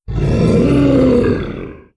08. Titan Roar.wav